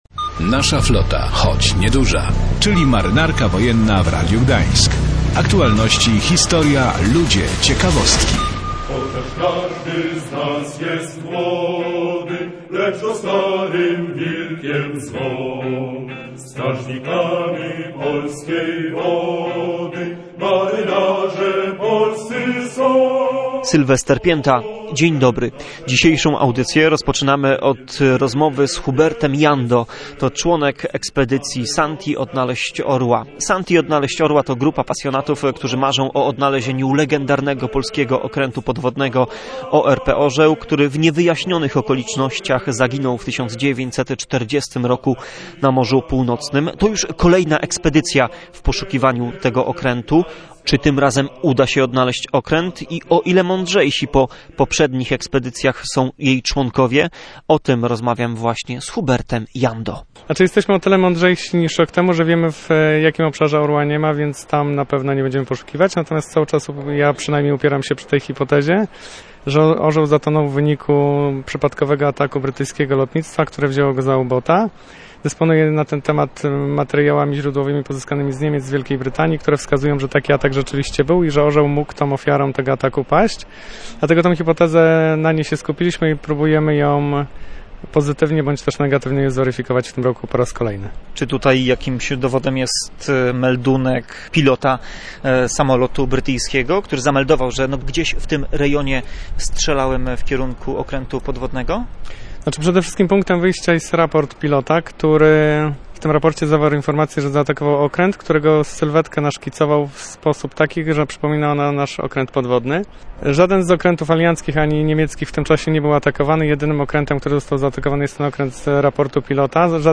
Dziś w naszej audycji na początek rozmowa